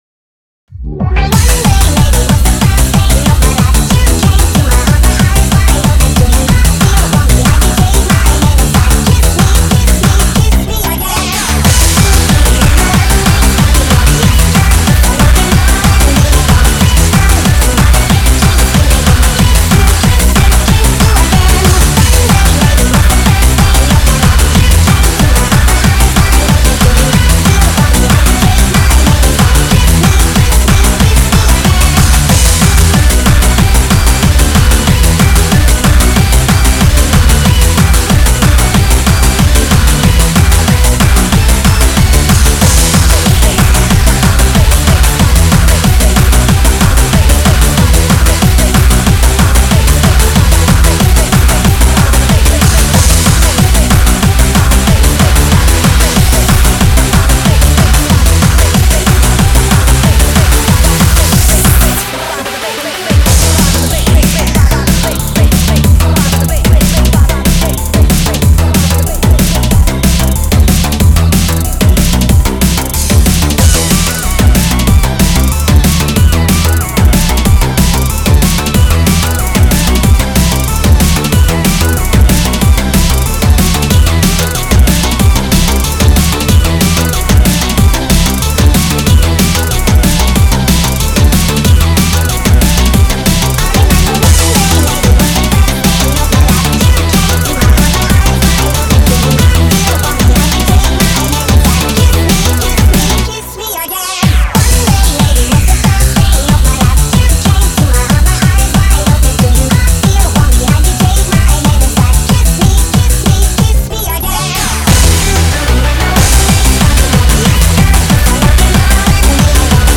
186 bpm